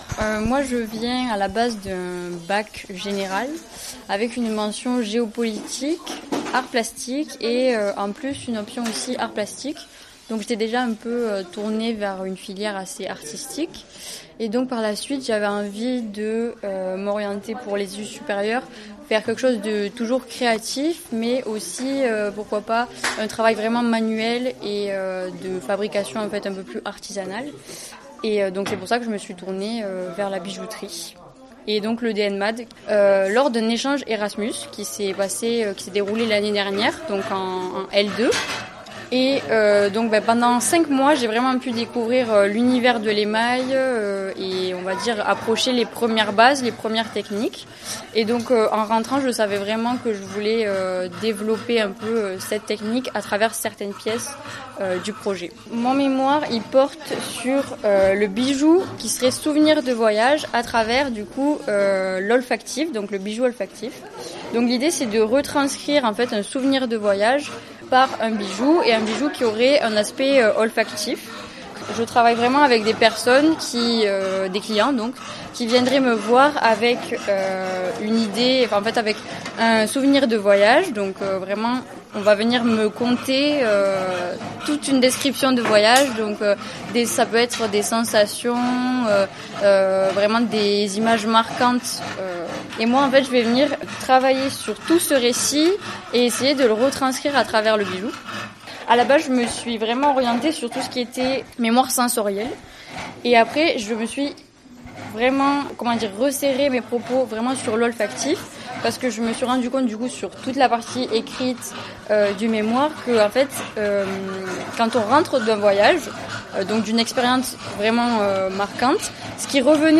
Afin de rendre compte de leur démarche, 7 étudiantes sur les 10 de cette 3e année sont interviewées dans le cadre de leur atelier et répondent chacune à 4 questions :
Un extrait de l’interview…